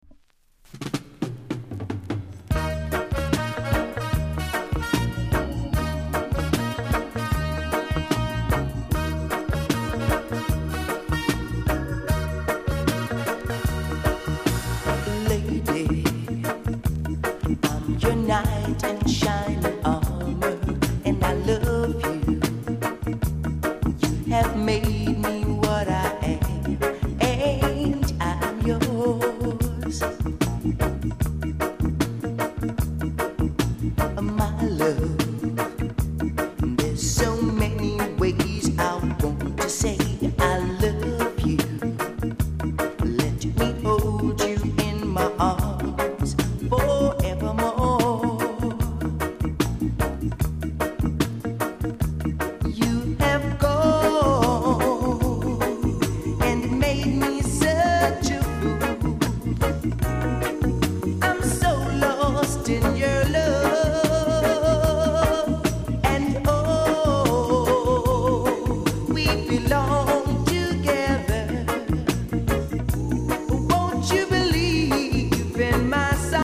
(WITH SAX)